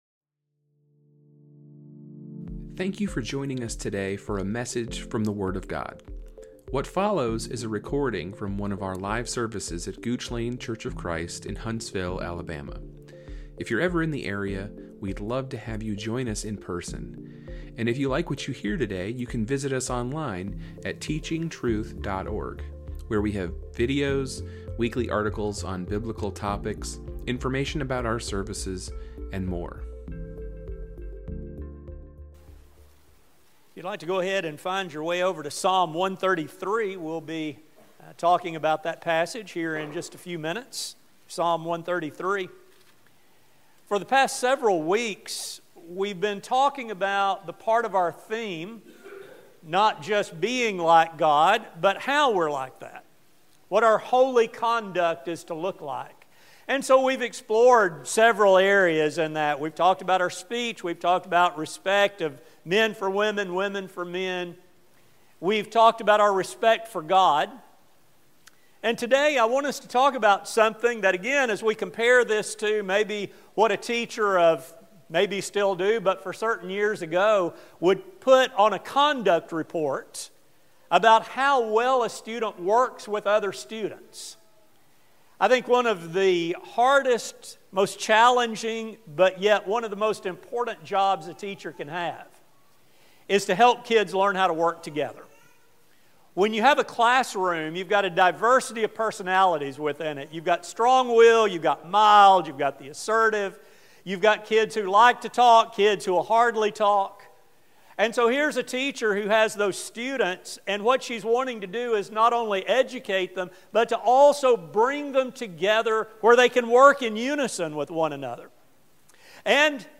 This sermon will explore this idea from a heavenly perspective. As God desires a holy people, what are His expectations for how members of this people get along?